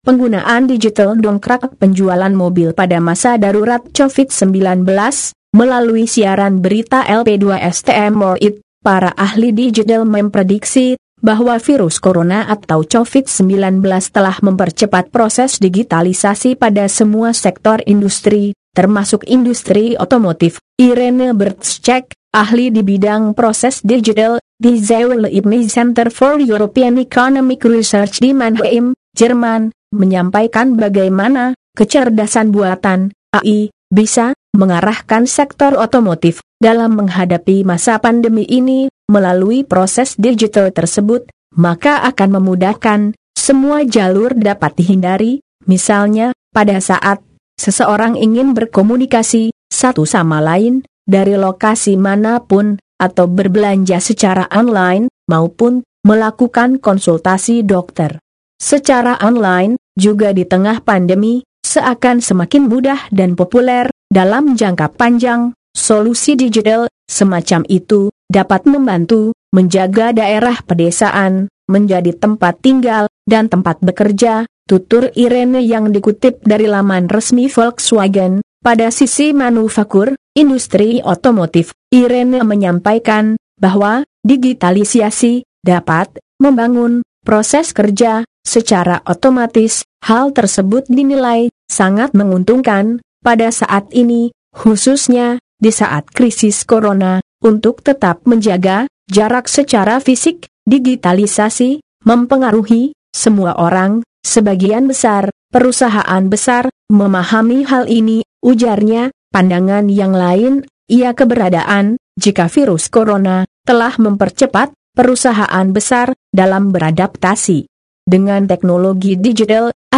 Format : Berita, music
Gaya penyiar   : Dewasa, dan smart